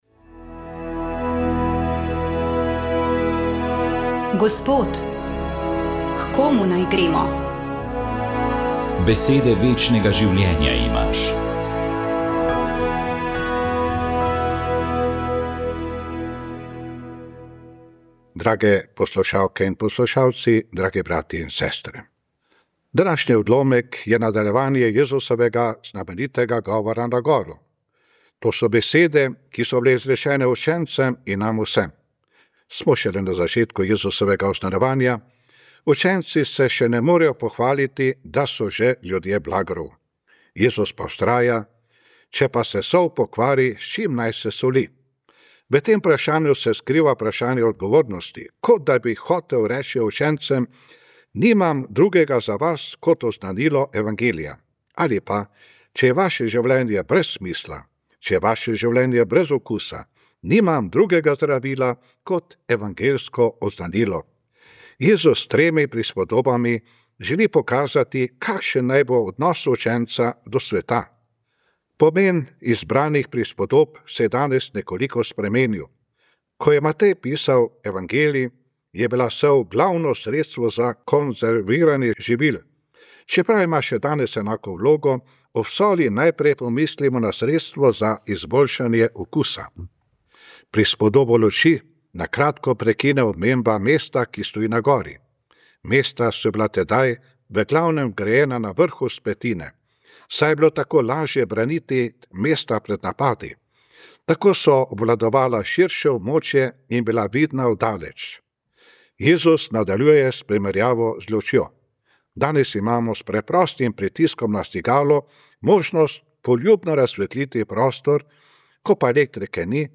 Duhovni nagovor
Duhovni nagovor je pripravil upokojeni ljubljanski nadškof dr. Anton Stres.